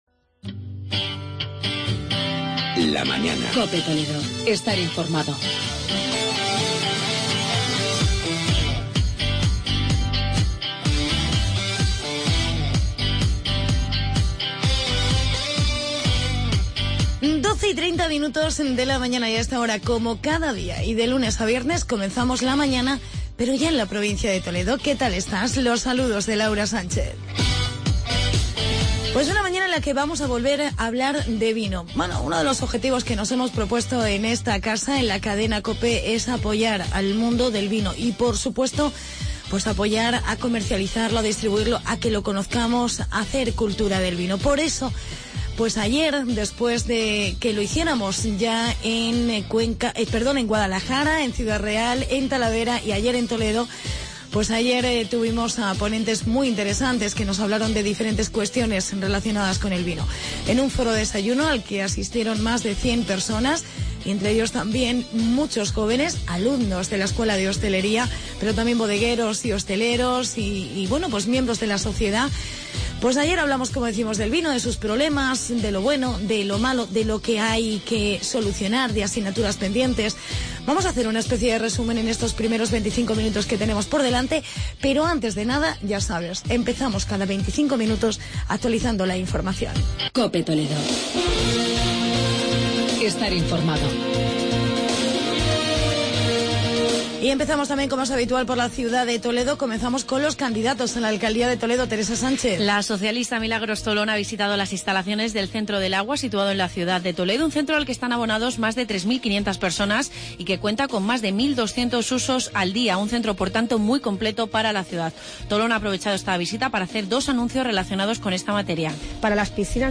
Reportaje "VINO EN POSITIVO" en Toledo.